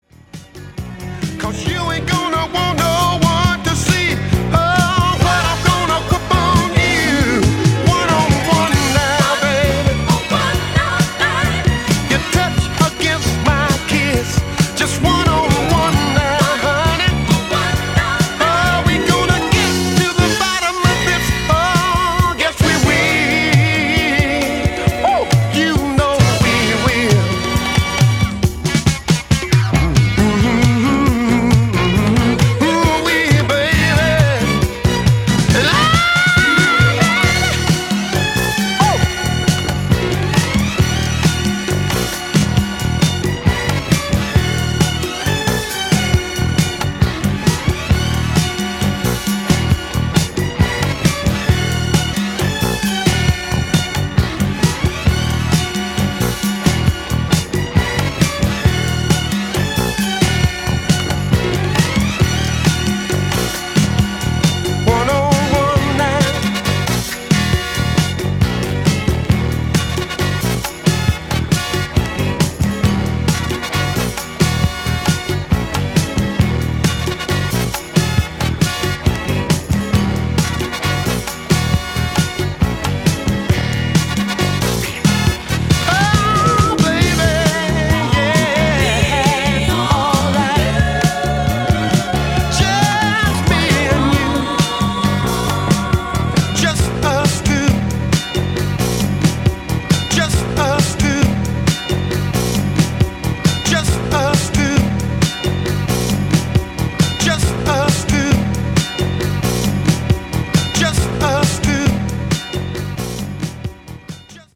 re-edit